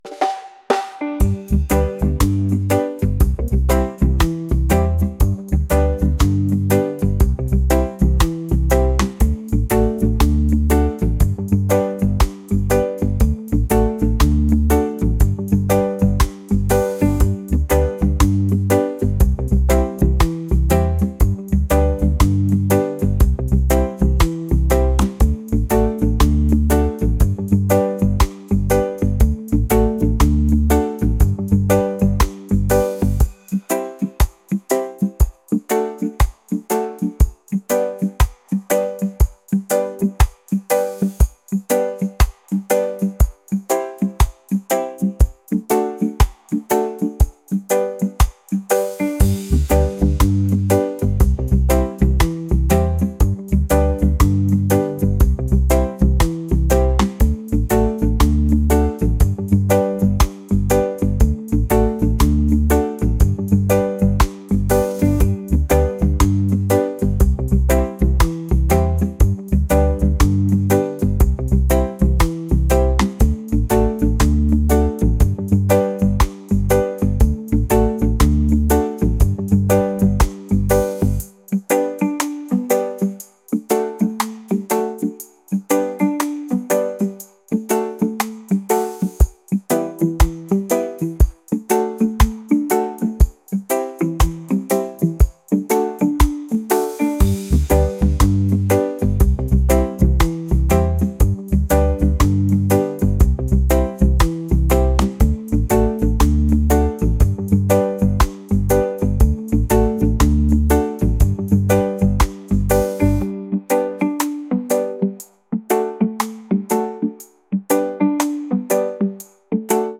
reggae | soul & rnb